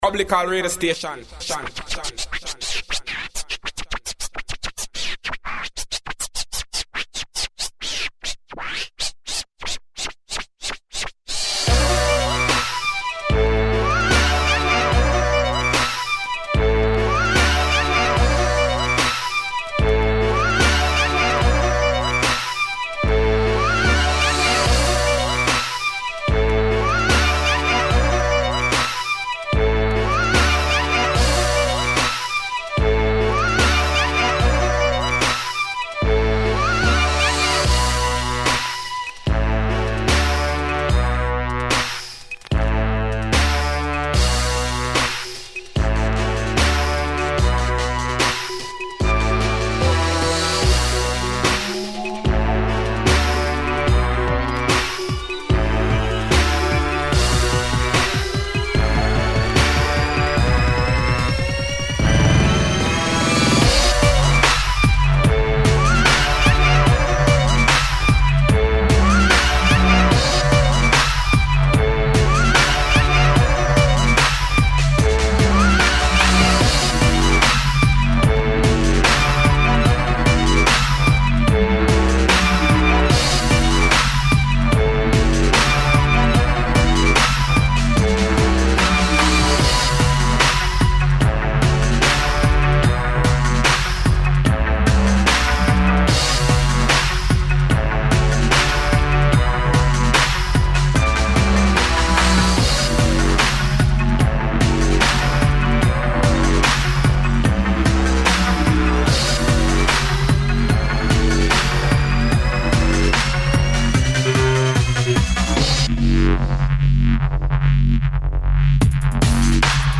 I scratch live on almost all my mixes...here's a couple